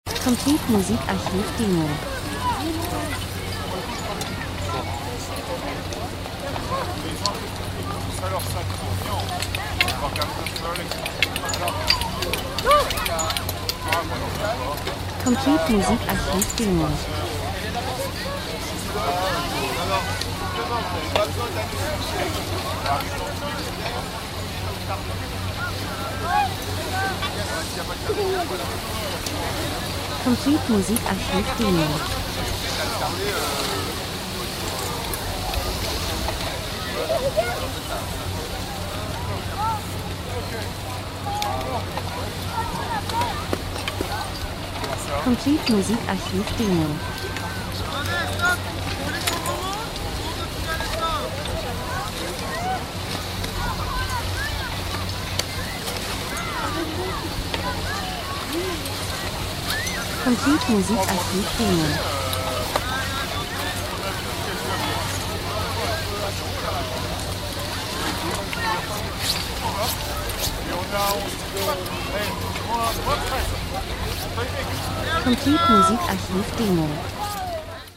Winter - Menschen Schnee Piste Ski 01:17